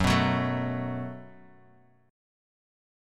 Gbmbb5 chord